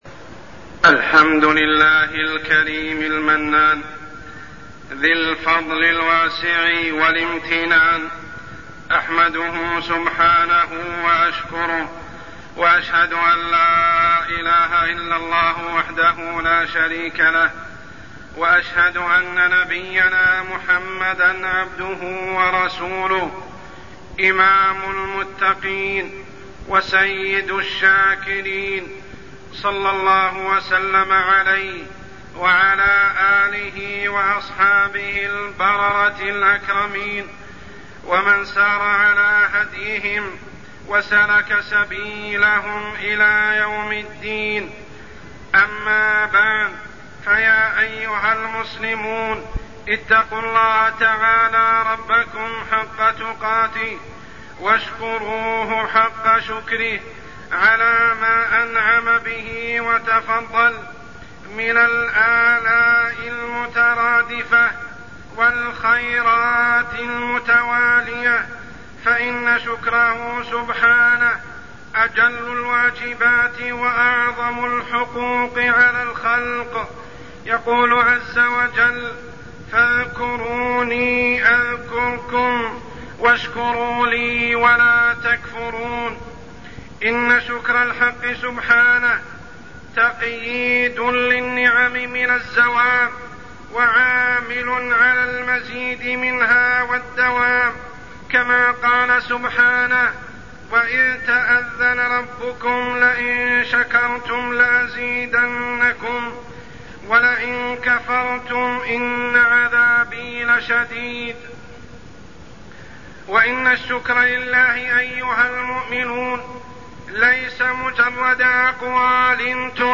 تاريخ النشر ١٦ صفر ١٤١٦ هـ المكان: المسجد الحرام الشيخ: عمر السبيل عمر السبيل الإسراف والتبذير